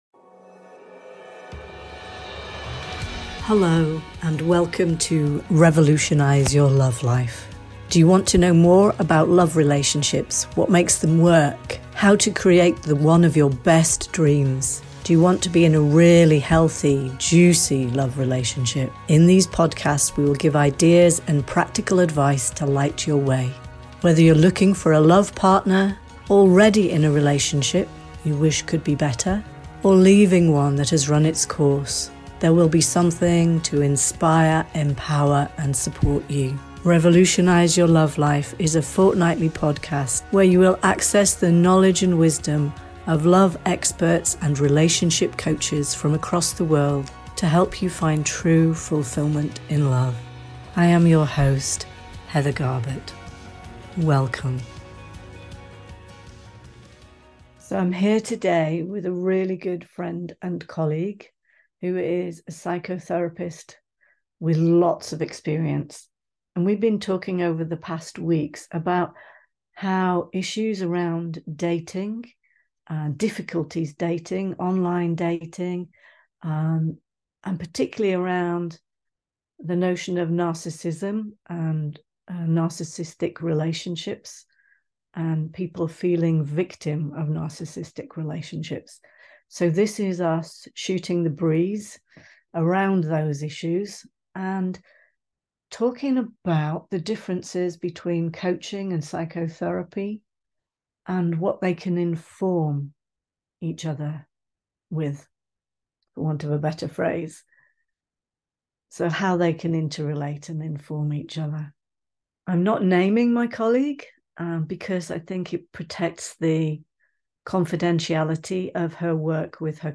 Personal anecdotes and a call for nuanced understanding of psychological issues enrich the dialogue.